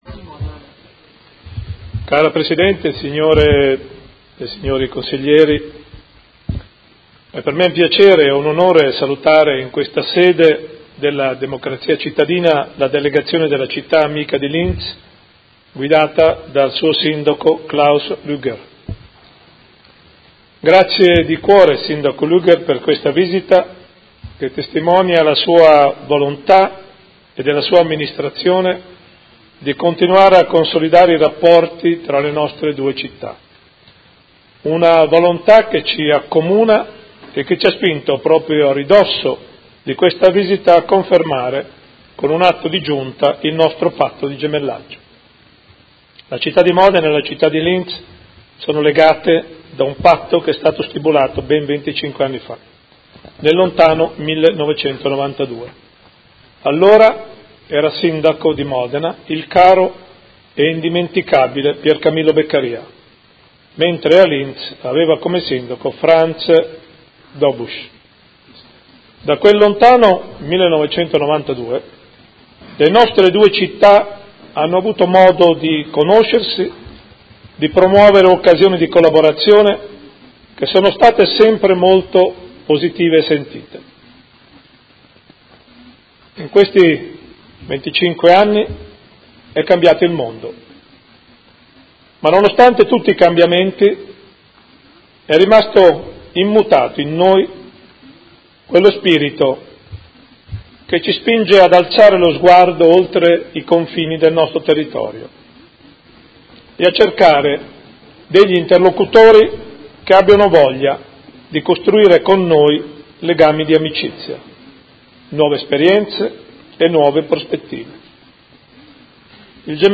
Seduta del 23/11/2007 Gemellaggio. Saluta il Sindaco del Comune di Linz ospite del Consiglio Comunale.